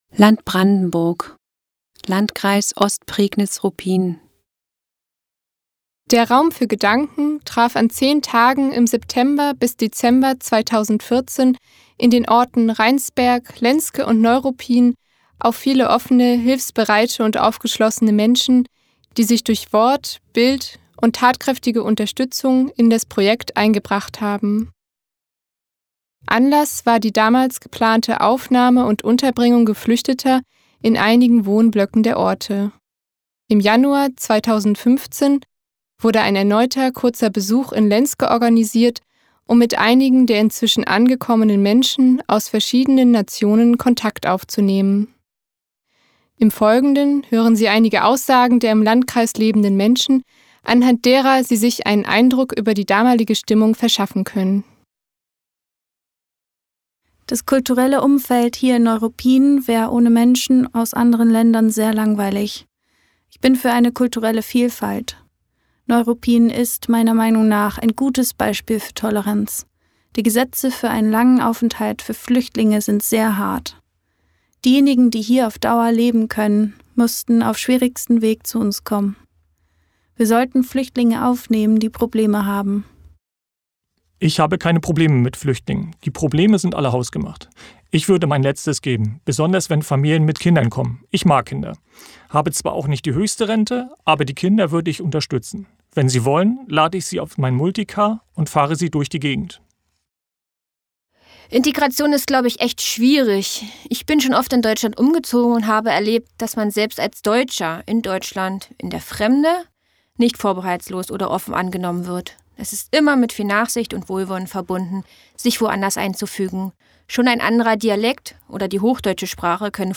In dieser Audio-Datei sind einige Interview-Auszüge aus Ostprignitz-Ruppin zu hören, in denen es um Nachbarschaften, Migration und Gemeinschaft geht.